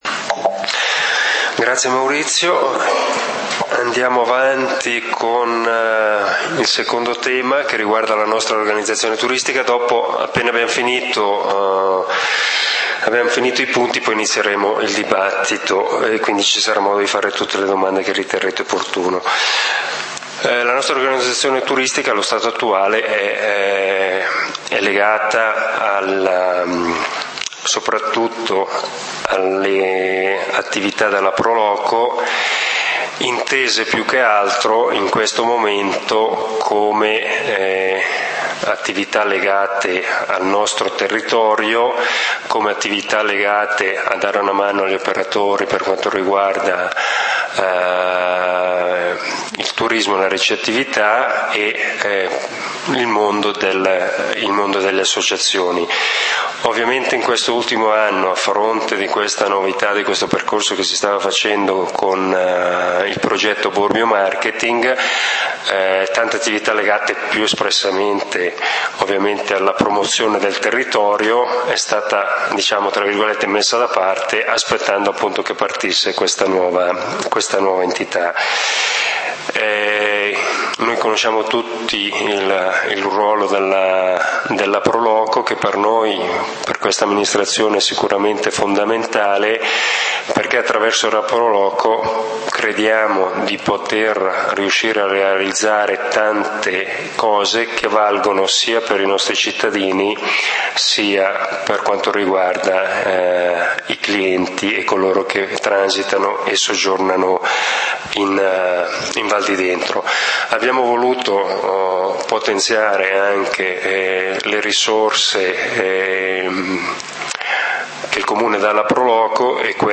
Turismo: informazione, confronto ed approfondimento Assembrela pubblica del comunale di Valdidentro del 14 Ottobre 2013